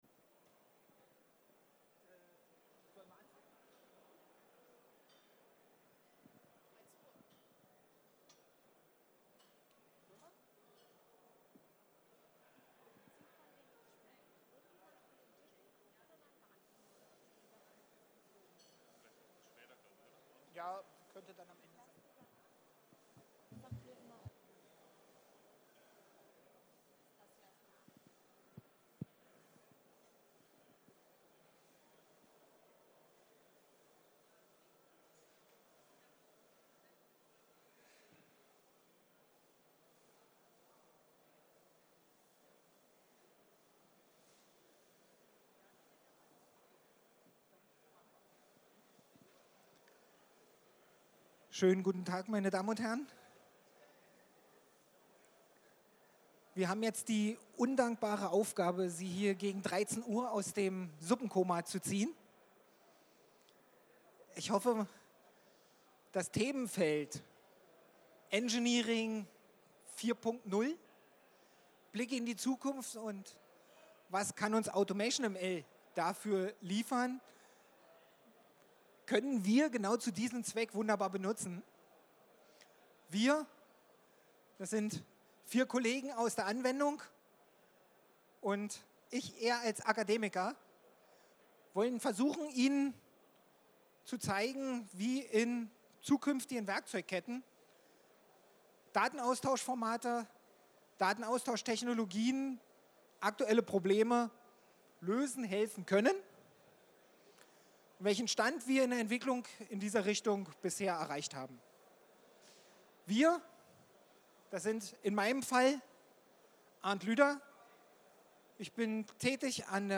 VDMA panel discussion available as a live record
You can download the live recording here for free.